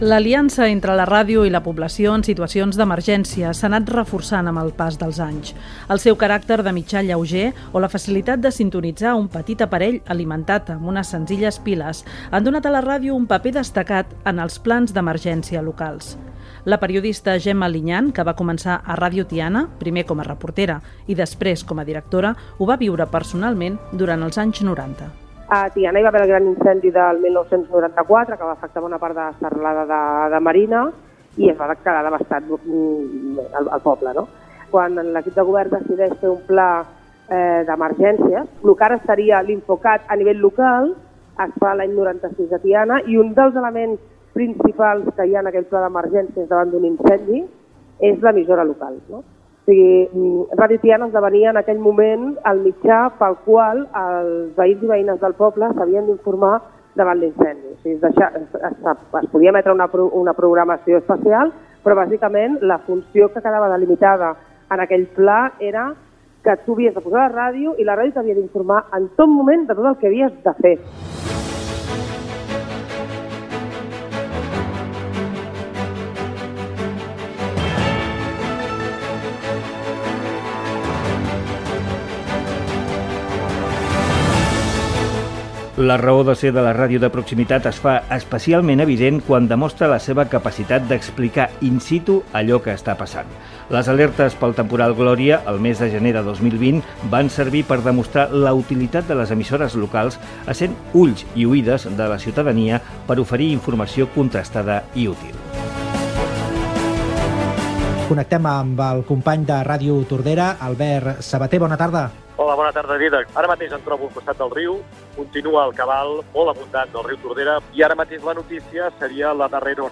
Ràdio Tiana (municipal)
Divulgació